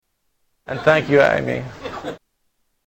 Category: Comedians   Right: Personal
Tags: Comedians Darrell Hammond Darrell Hammond Impressions SNL Television